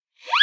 cartoon-throw
Category 🤣 Funny
cartoon comic fall funny pitch remix request siren-whistle sound effect free sound royalty free Funny